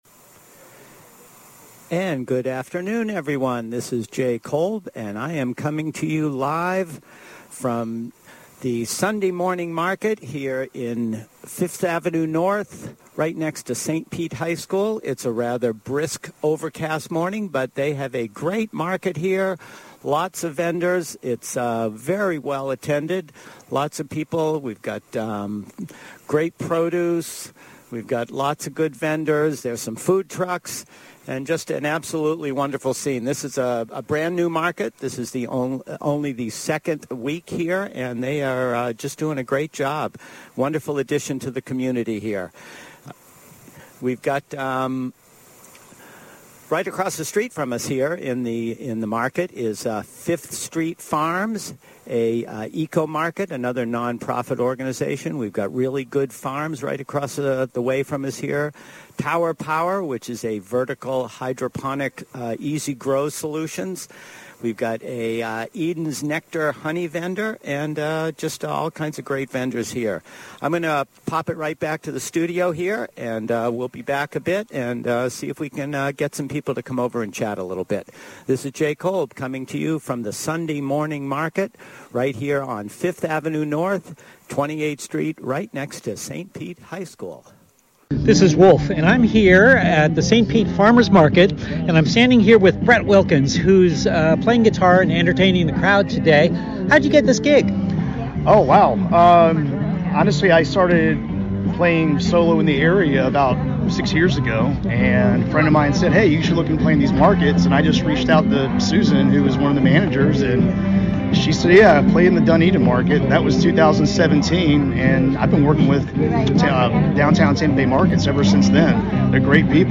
RadioStPete Live at St. Pete Sunday Market 1-14-24